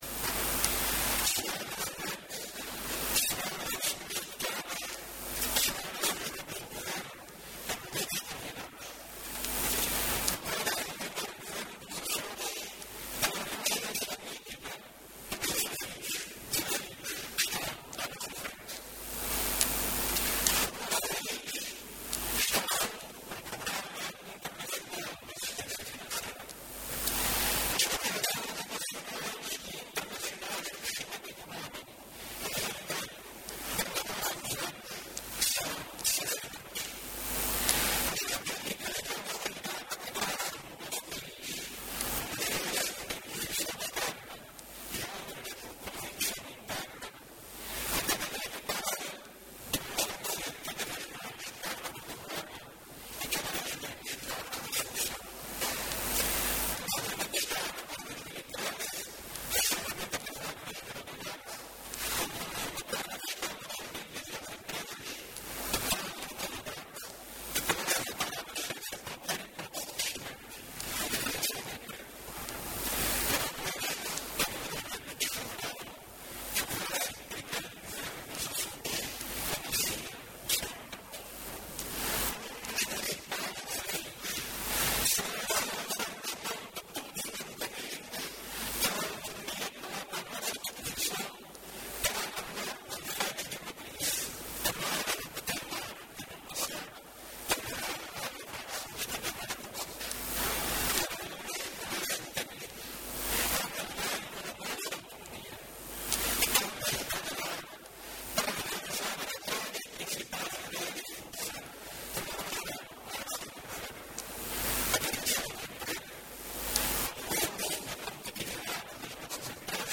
Notícias dos Açores: Intervenção do Presidente do Governo na Sessão Solene da Tomada de Posse do XI Governo dos Açores